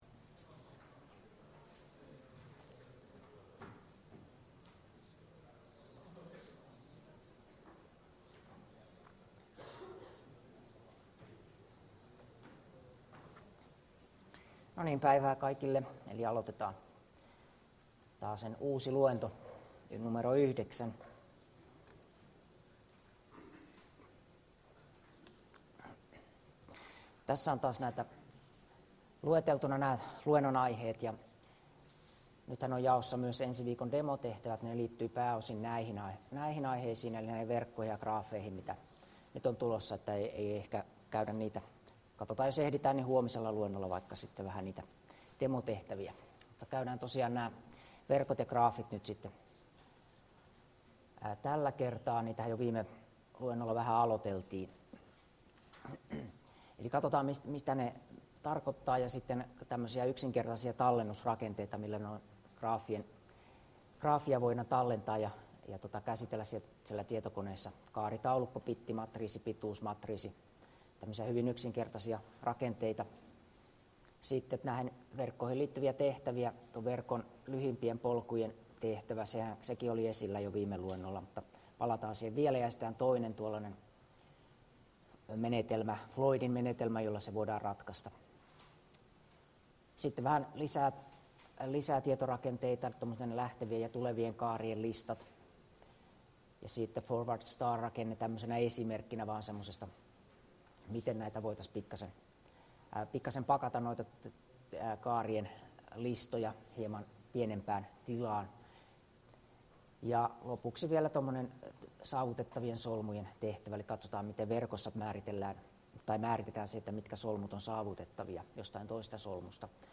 Luento 9 — Moniviestin